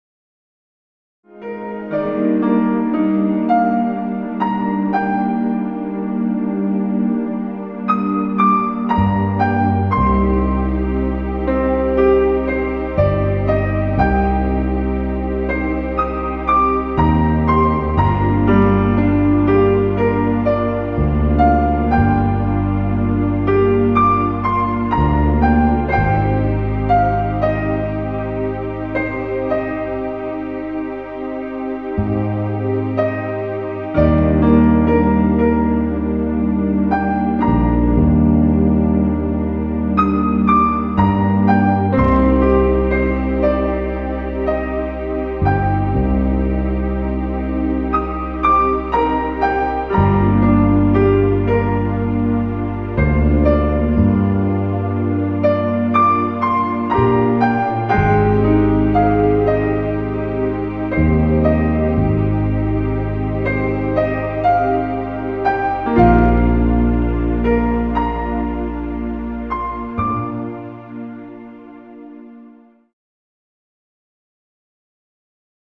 Wellness-Entspannungsmusik.